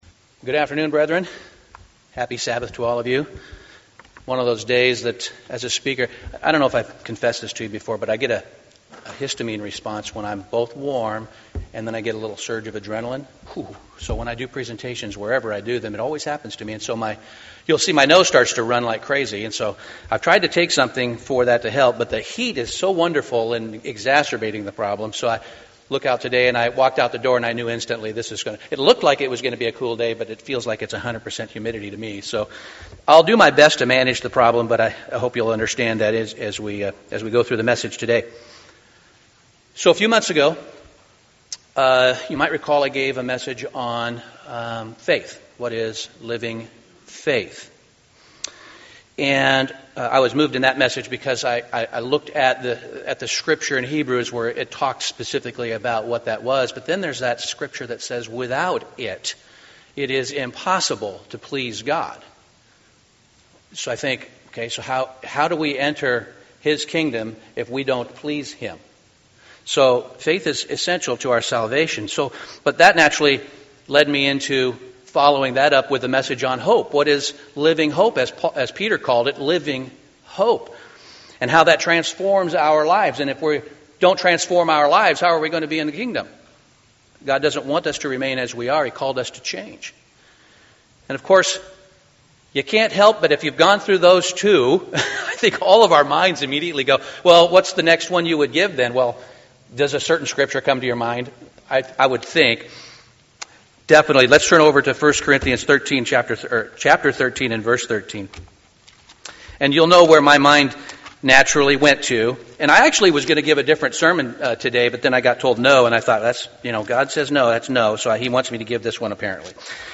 Sermons
Given in Portland, OR